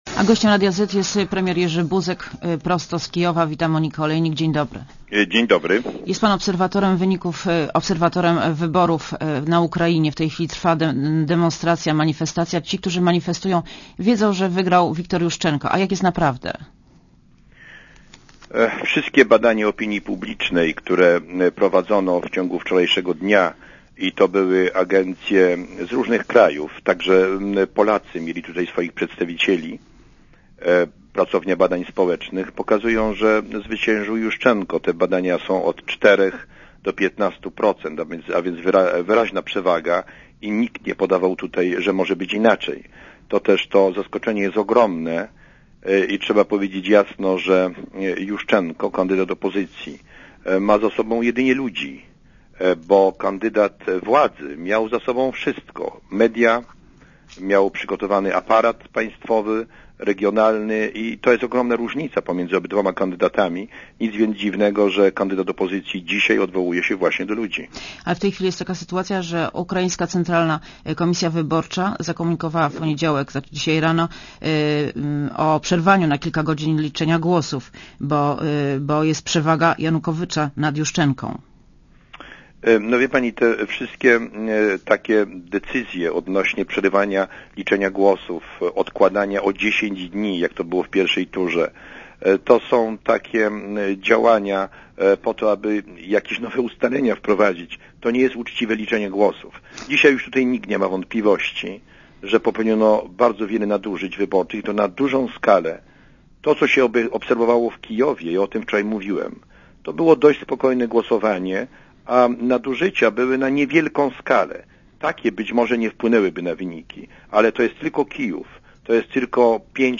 Gościem Radia Zet jest były premier Jerzy Buzek, prosto z Kijowa.